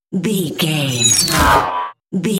Sci fi appear whoosh
Sound Effects
futuristic
high tech
whoosh